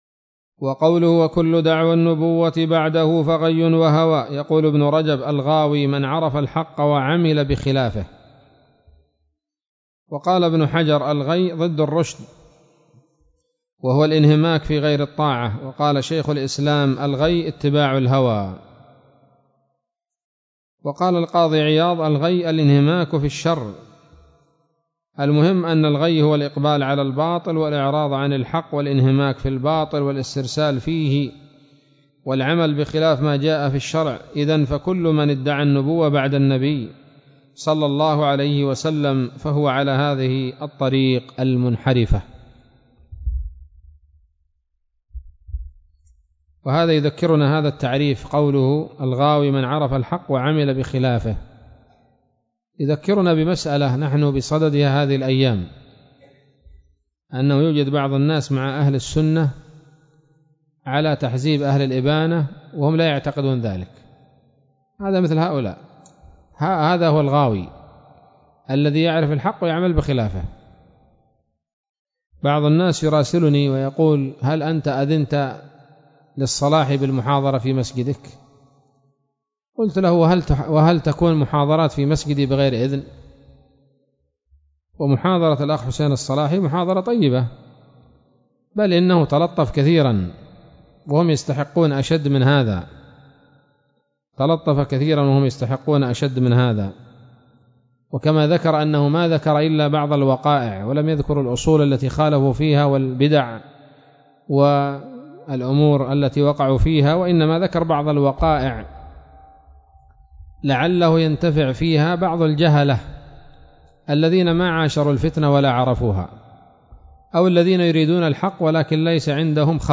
كلمة بعنوان : (( التحذير من الغواية )) ليلة الإثنين 15 من شهر صفر 1441 هـ